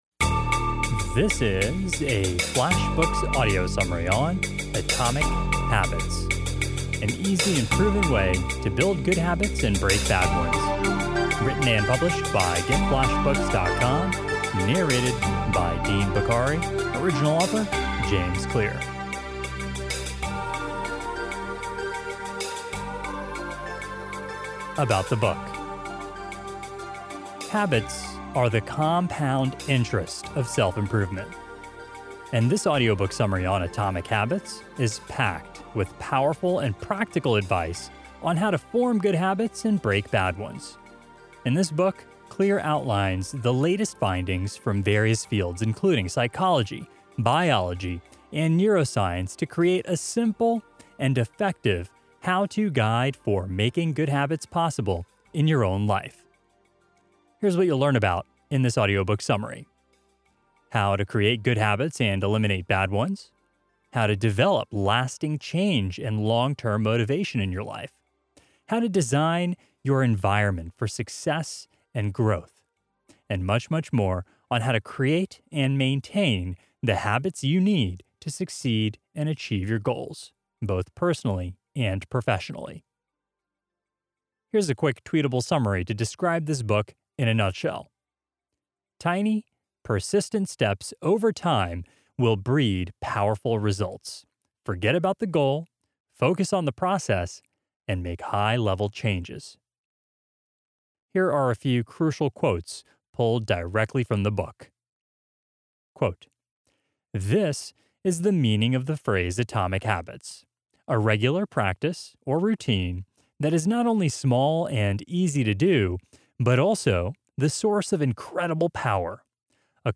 AtomicHabitsbyJamesClear-AudiobookSummaryMP3.mp3